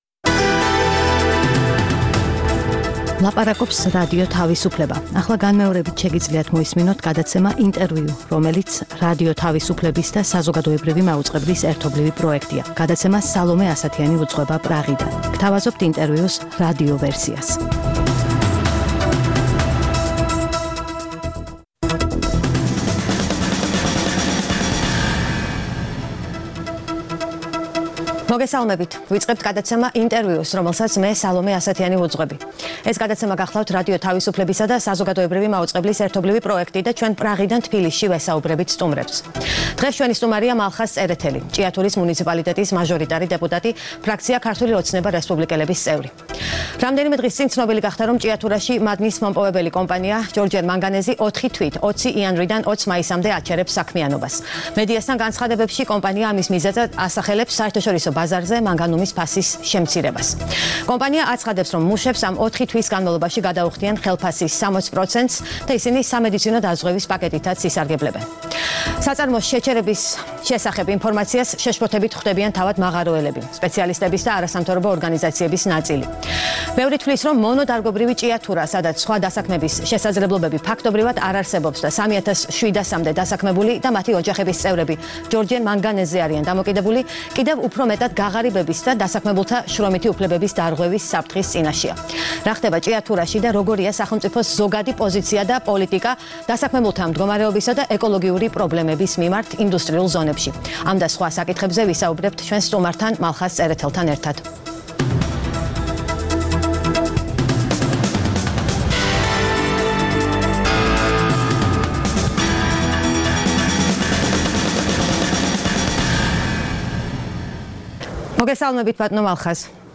ყოველკვირეული გადაცემა „ინტერview“ არის რადიო თავისუფლებისა და საზოგადოებრივი მაუწყებლის ერთობლივი პროექტი. მასში მონაწილეობისთვის ვიწვევთ ყველას, ვინც გავლენას ახდენს საქართველოს პოლიტიკურ პროცესებზე. „ინტერview“ არის პრაღა-თბილისის ტელეხიდი
რადიო თავისუფლების პრაღის სტუდიიდან